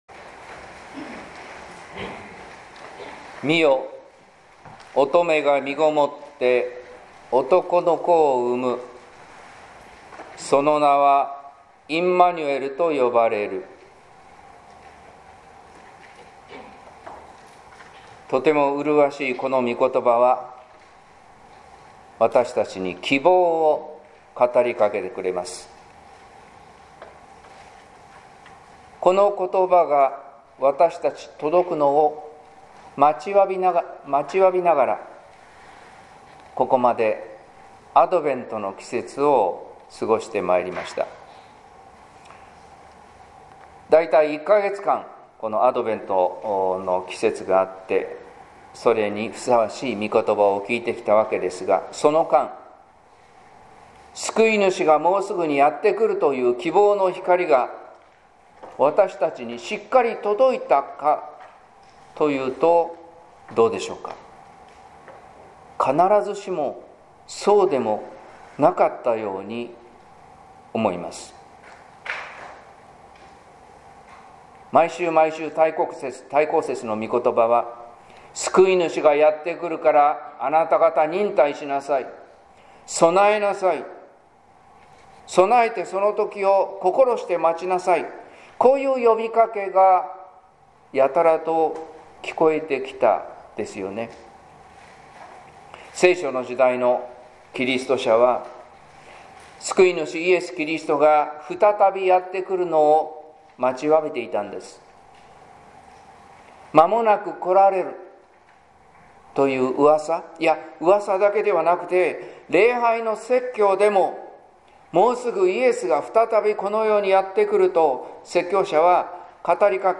説教「肉にして聖なるイエス」（音声版） | 日本福音ルーテル市ヶ谷教会